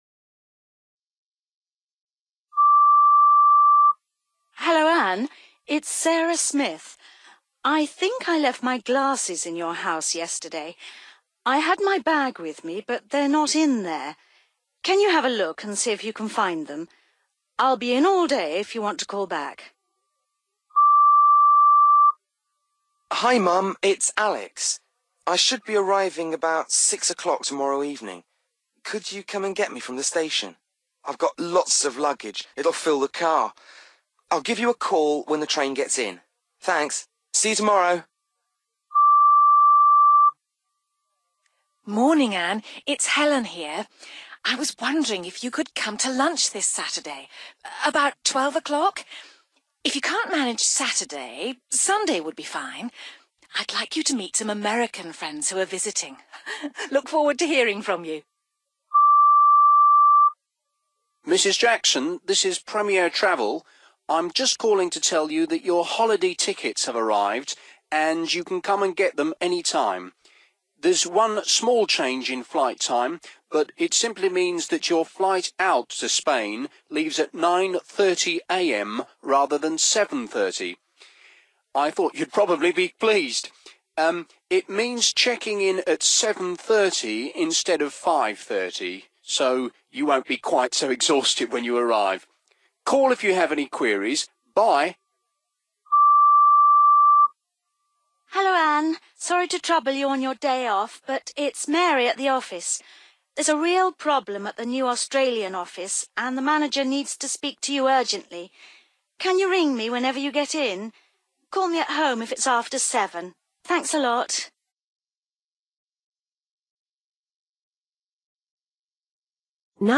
You will hear five messages left on an answerphone.